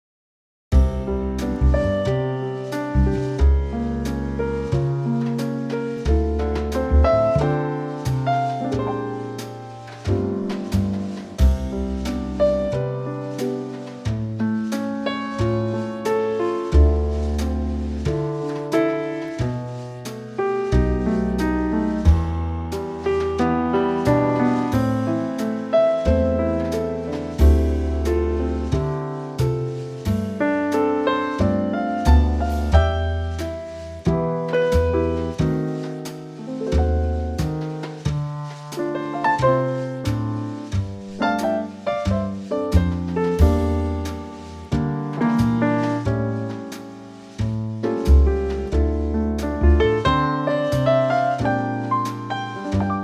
Unique Backing Tracks
key - C - vocal range - B to C